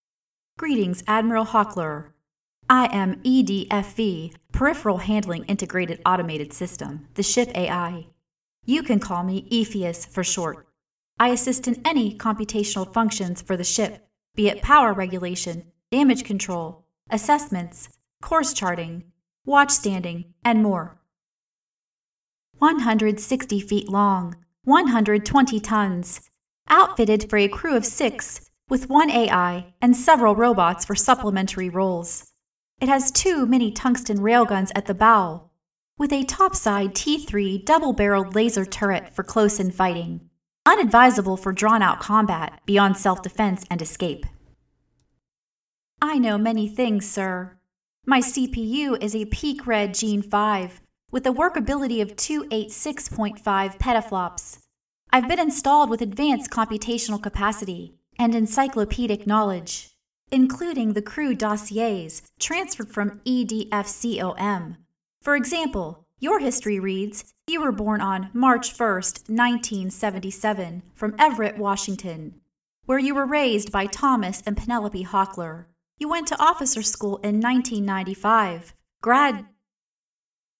I’m attaching an audition I read that got me a part as an AI in a sci-fi novel.